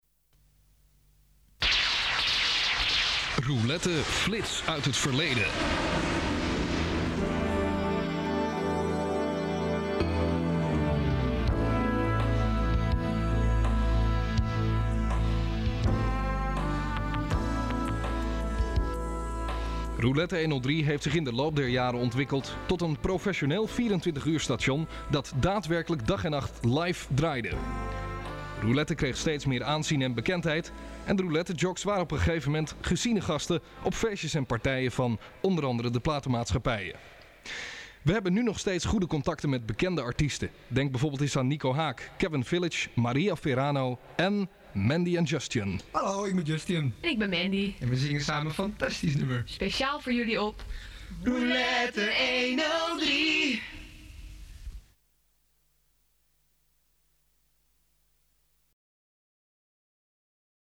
Alles op cassette en spoelen (bandrecorders)